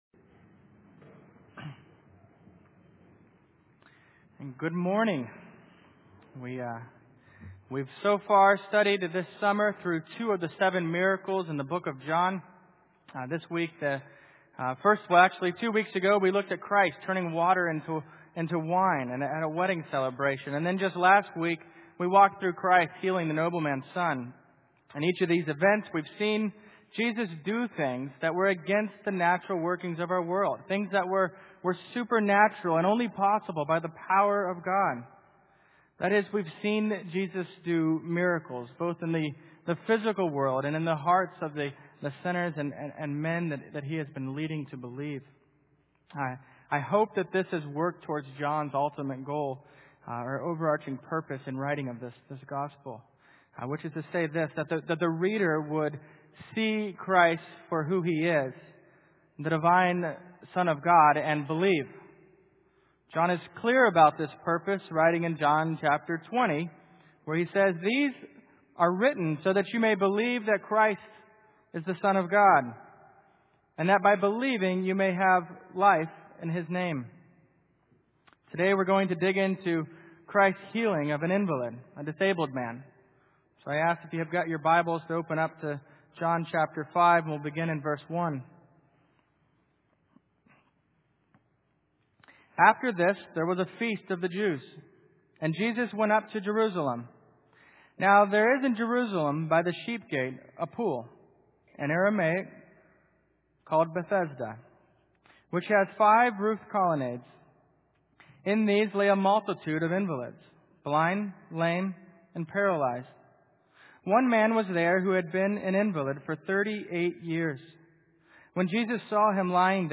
Miracles of Jesus in the Gospel of John Passage: John 5:1-17 Service Type: Morning Worship « Jesus did What?